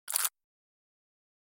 دانلود آهنگ مزرعه 4 از افکت صوتی طبیعت و محیط
جلوه های صوتی